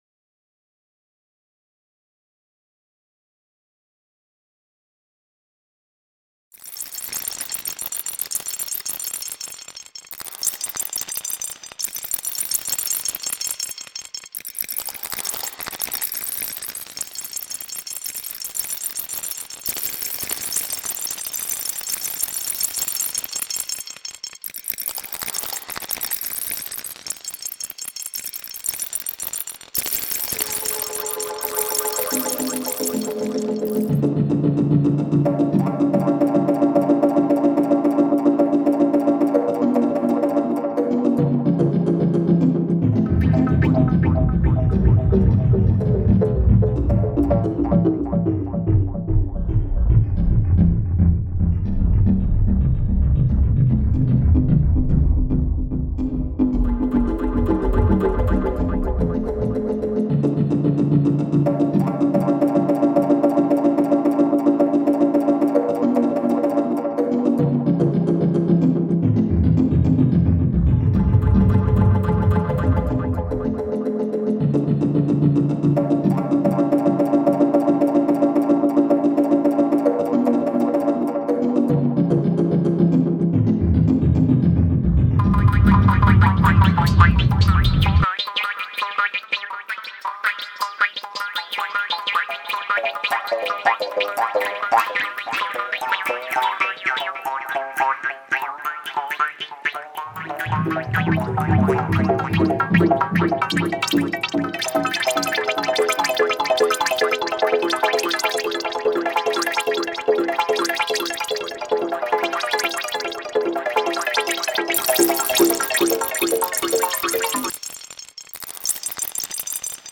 ‘Woodjaw’  (GB 2015) ::: is a sound piece made with reconfigured recordings of a bamboo jaw harp.